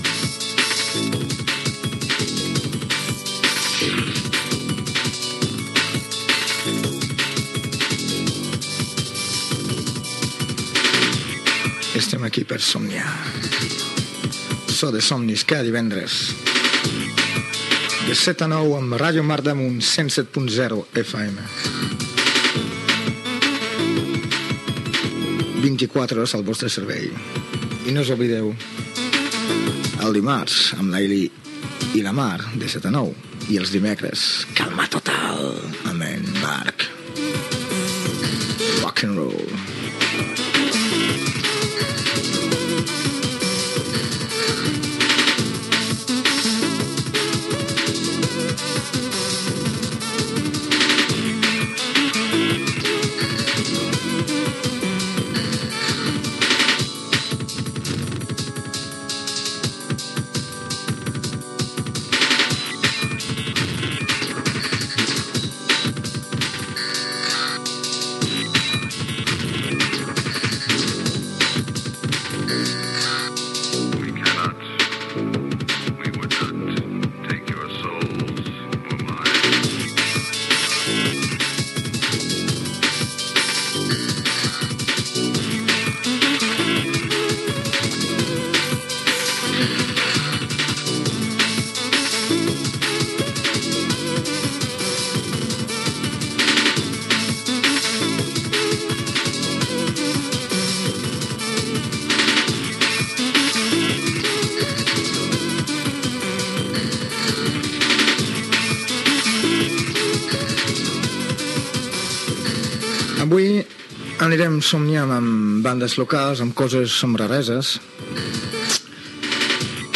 Presentació del programa amb identificació de l'emissora, altres espais de la ràdio i tema musical
Musical
FM